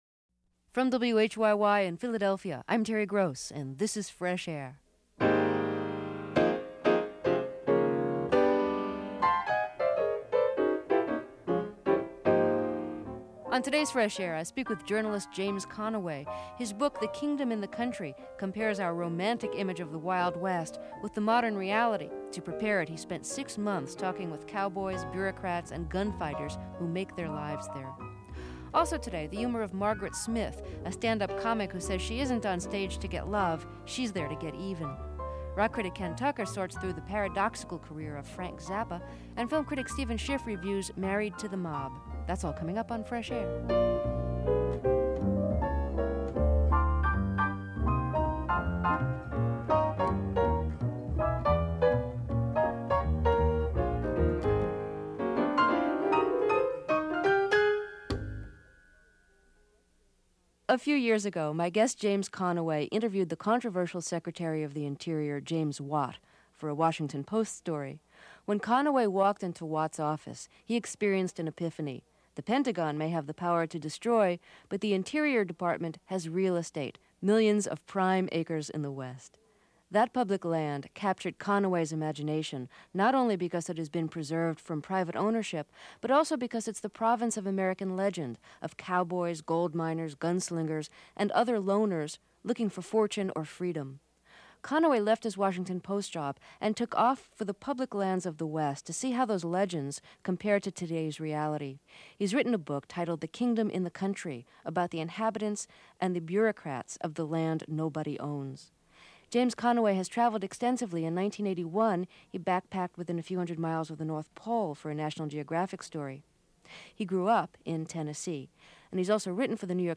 Comedian Kate Clinton.